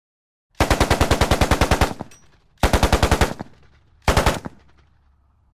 Звуки пулемёта
Звук стрельбы из пулемёта — 9 вариант